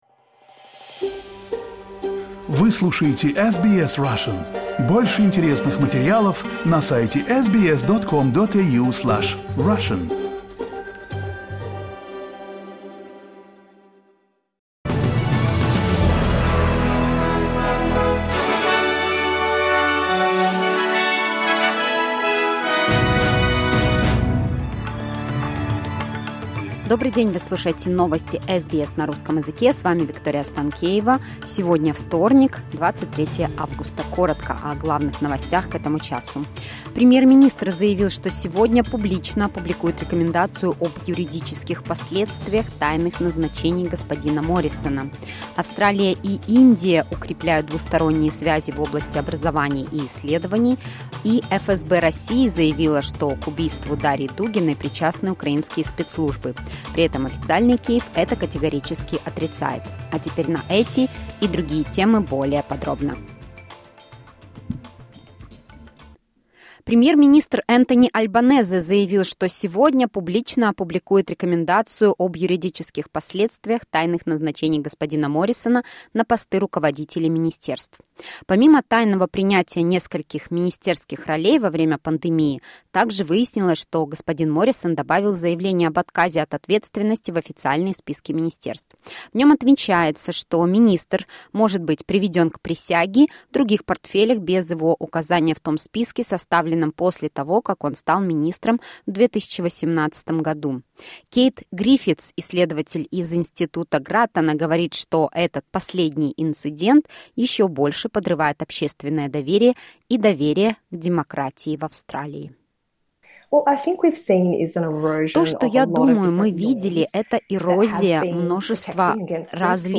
SBS Russian program live - 23.08.2022
Listen to the latest news headlines in Australia from SBS Russian.